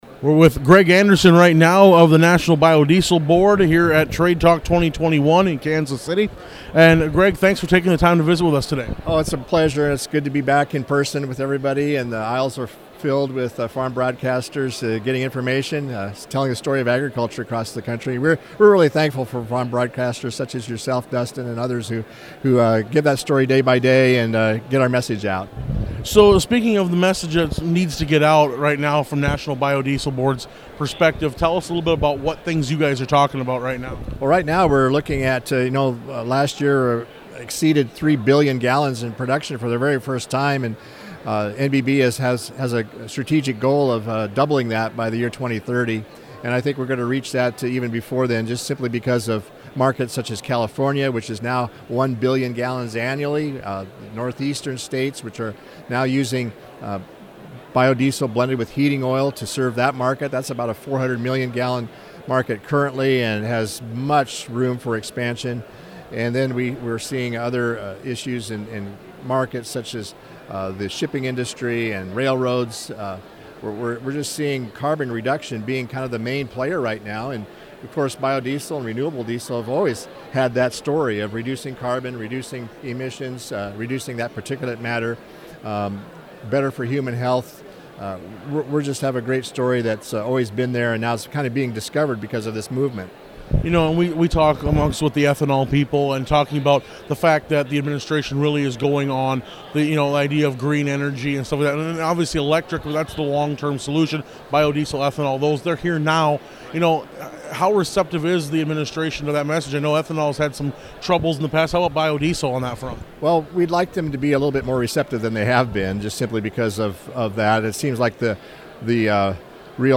Audio: Full interview